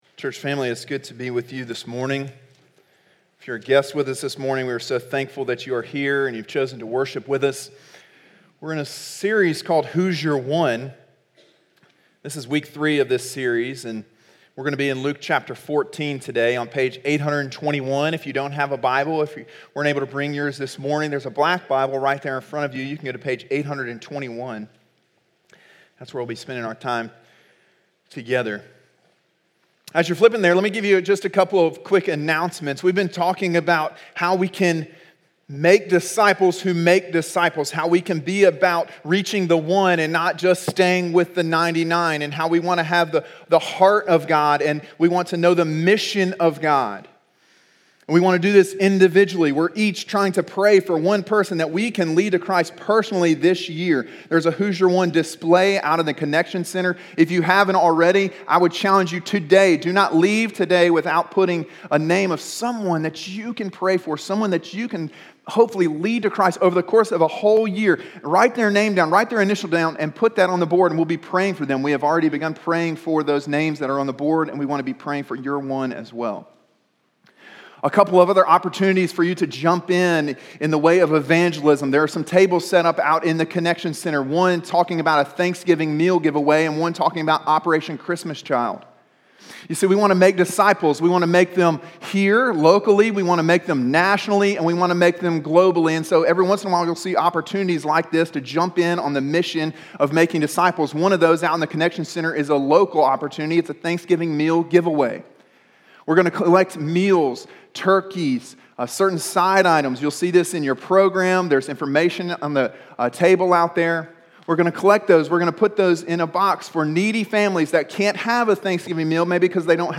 Sermon: “One Interesting Banquet” (Luke 14:15-24) – Calvary Baptist Church